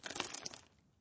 描述：吱吱作响的皮革，多个吱吱作响。用一个皮革手提包做的，但被用作吱吱作响的皮革切斯特菲尔德椅子的假音
标签： 吱吱吱吱作响 皮革
声道立体声